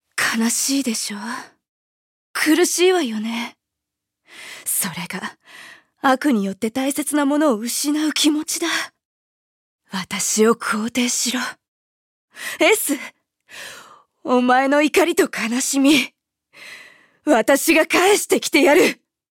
음성 대사